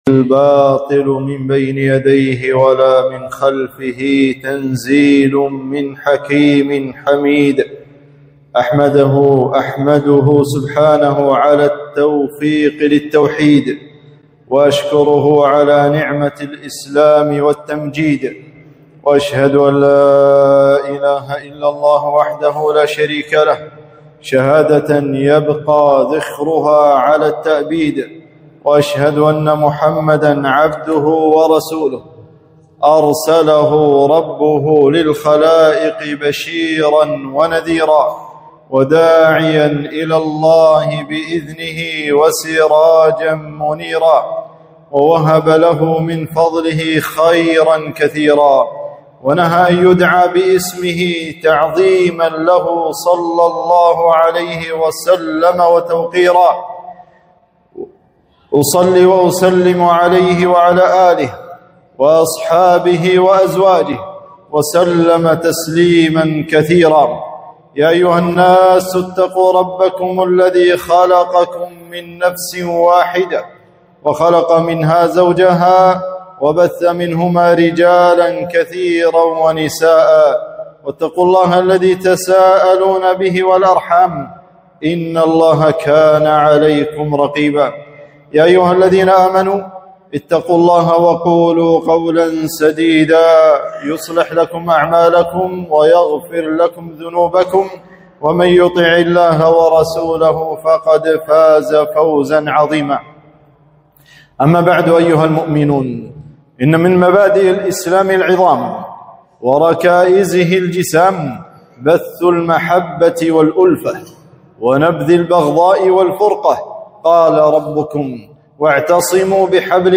خطبة - التذكير بصلة الأرحام